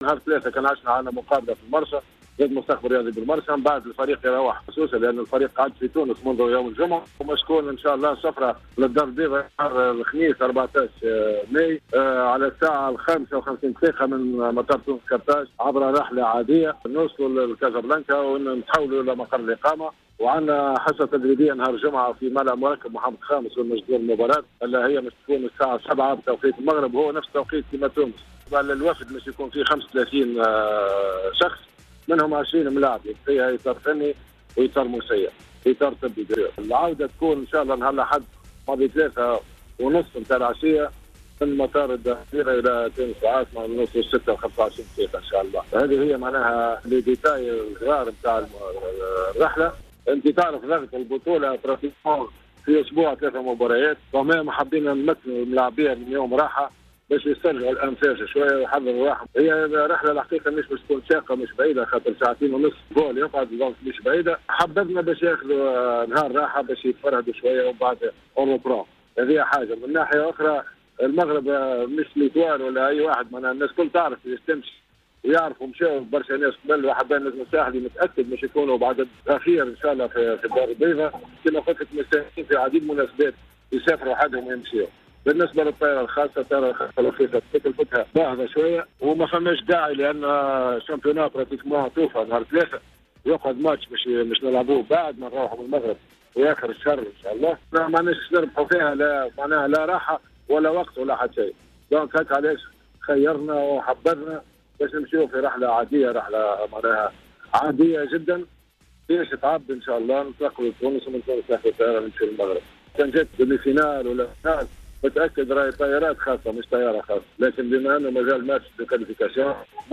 مداخلة في برنامج راديو سبور على جوهرة أف أم